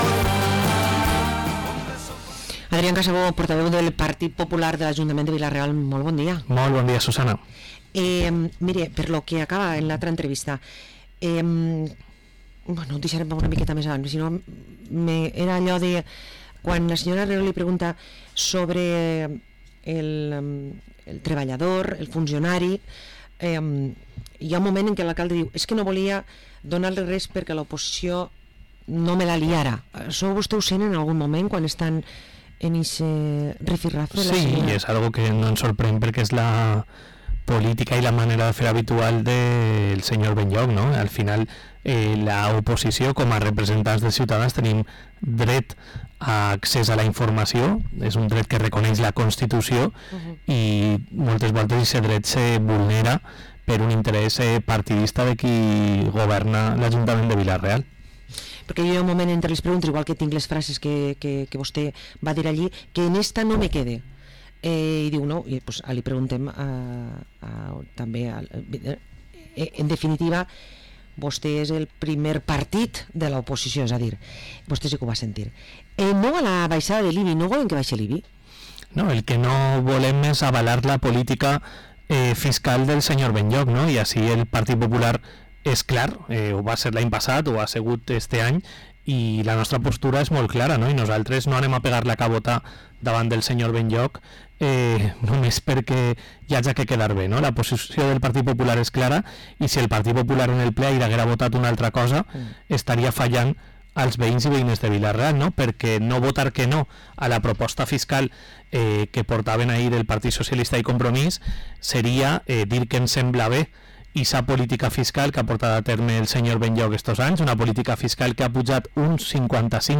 Parlem amb Adrián Casabó, portaveu i regidor del PP a l´Ajuntament de Vila-real